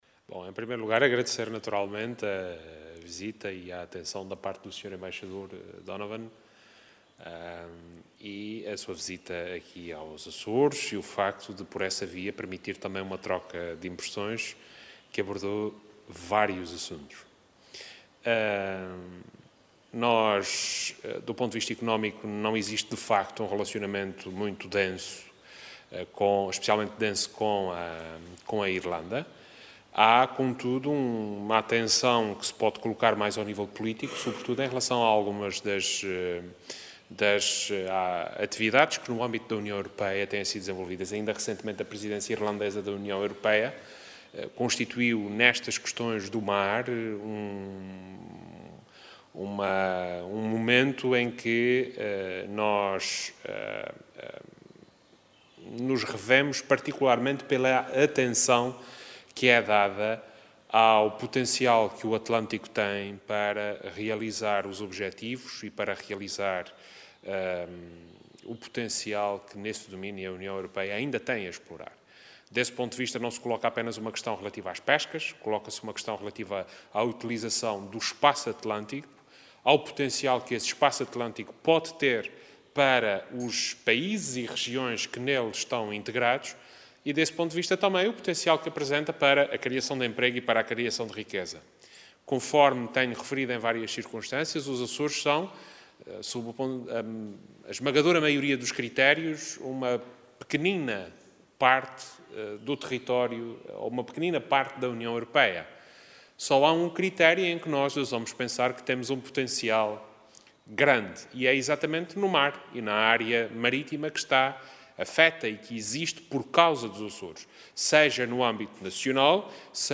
“Revemo-nos na atenção que a Irlanda colocou neste processo e acompanhamos o esforço para valorizar este espaço atlântico, como espaço com potencial de criação de riqueza e de criação de emprego”, afirmou Vasco Cordeiro, em declarações aos jornalistas no final de uma audiência com o Embaixador da Irlanda em Portugal, Declan O’Donovan.